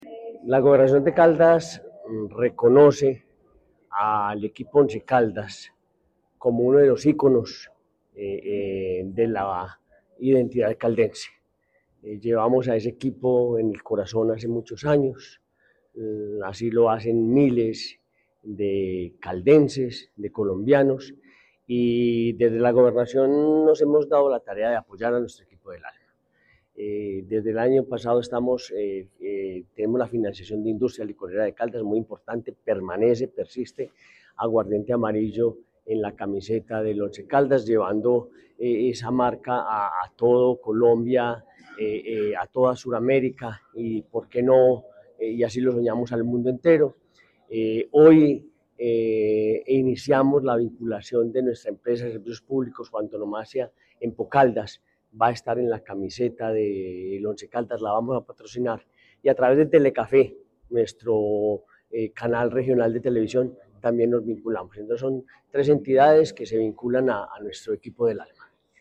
Gobernador de Caldas, Henry Gutiérrez Ángel.